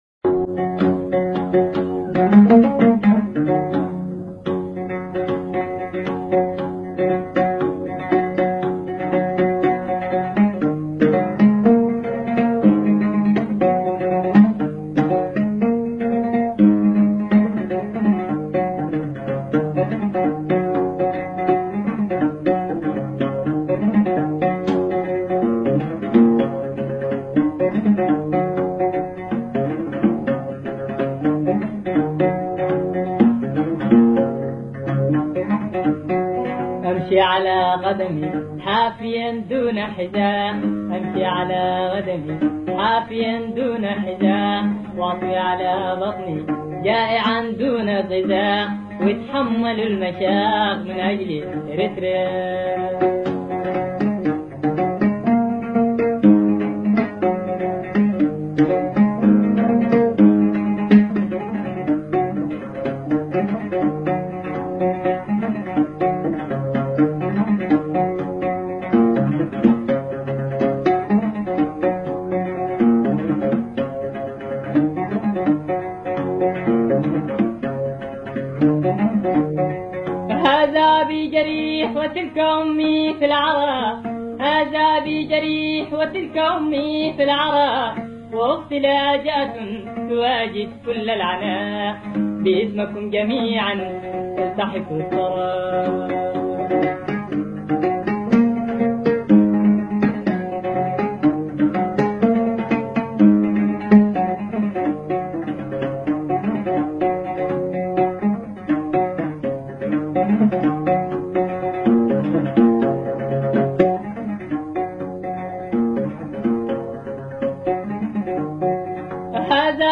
أغنية